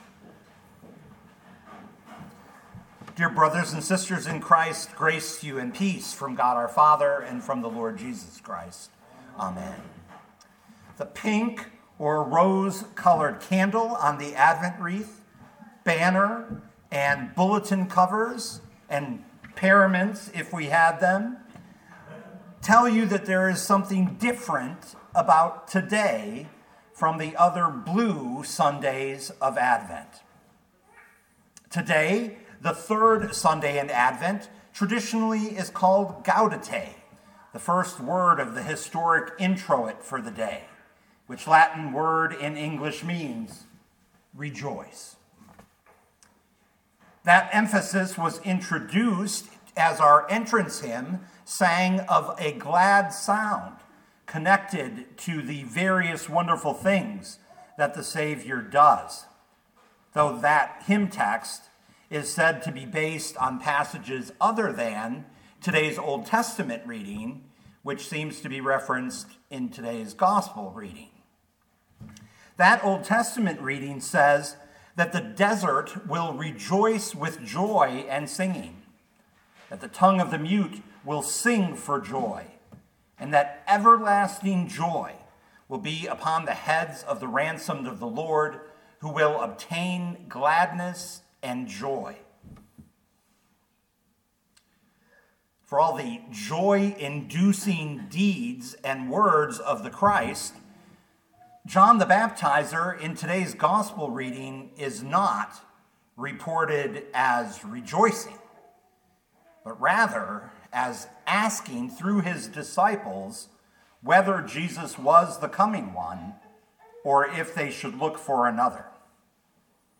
2025 Matthew 11:2-15 Listen to the sermon with the player below, or, download the audio.